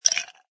ice2.mp3